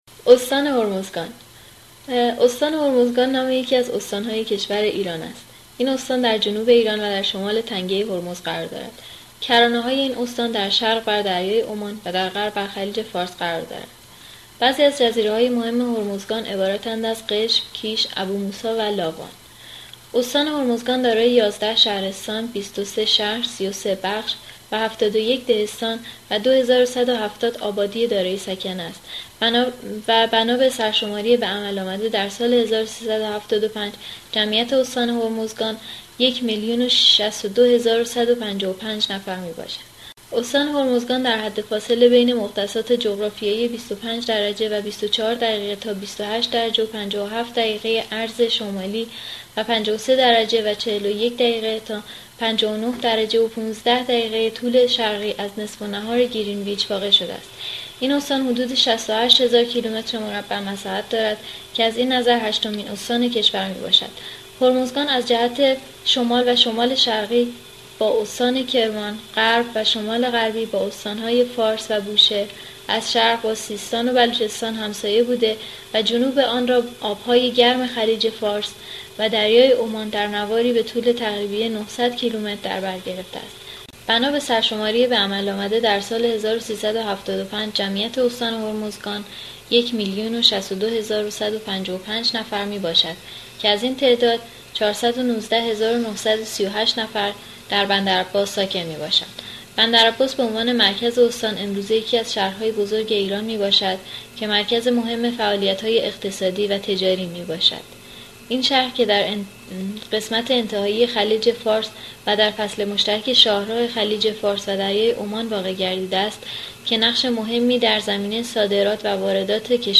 reading of a long article  from Wikipedia.